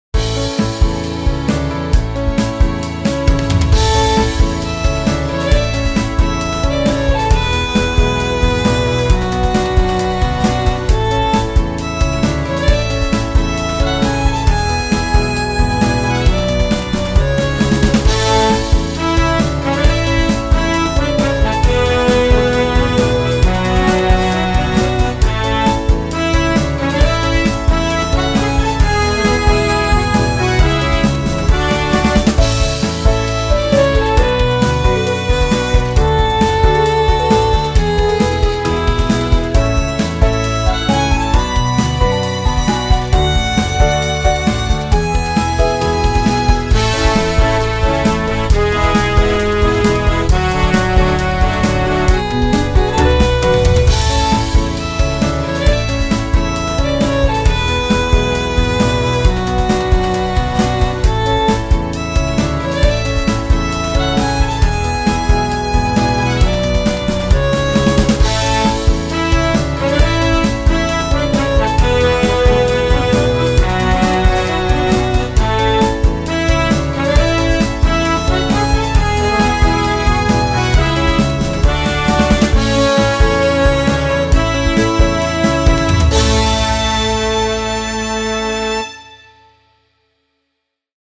明るく楽し気なバイオリンが印象的な、明るく前向きな雰囲気のBGMです。
バイオリン サックス